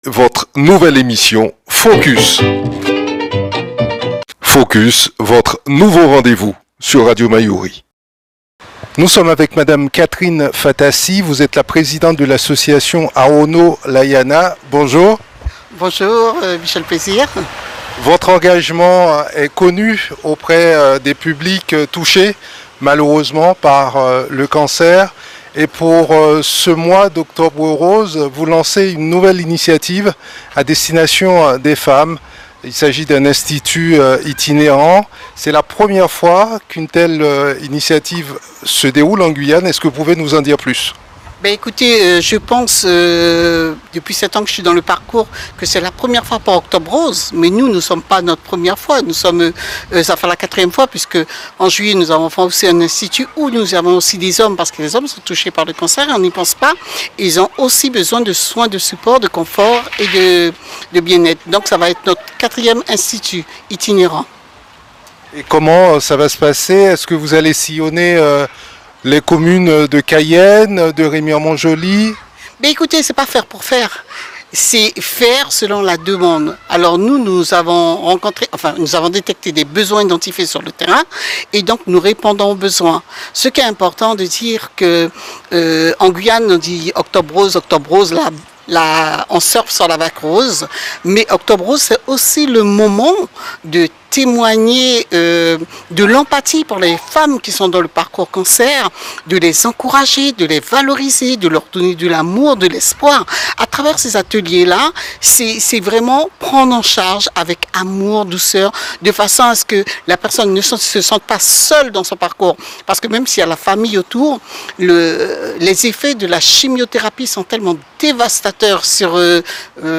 Octobre rose oblige, l'association propose aux femmes des soins de support confort et de bien-être pour la première fois en Guyane dans un institut itinérant à Rémire-Montjoly le samedi 21 octobre, où elles seront chouchoutées, valorisées par 7 professionnels. Elle est notre invitée ce jeudi dans la rubrique FOCUS pour en parler.